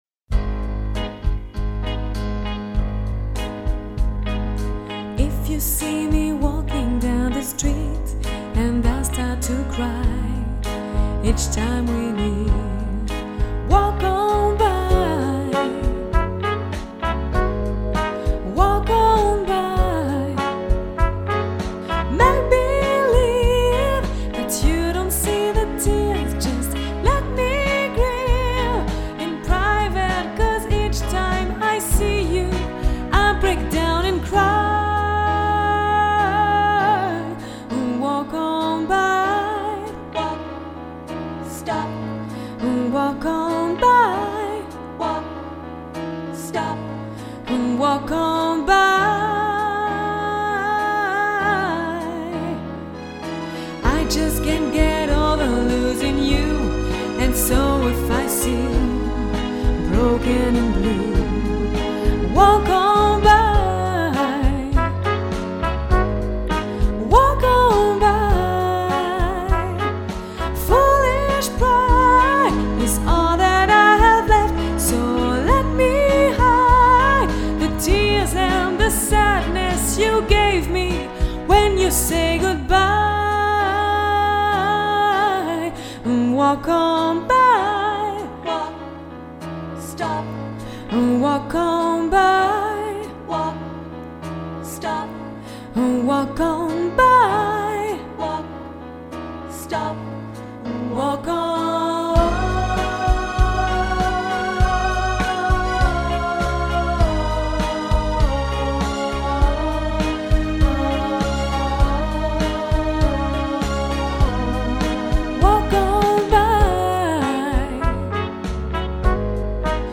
Chanteuse
35 - 48 ans - Mezzo-soprano